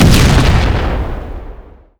etfx_explosion_nuke.wav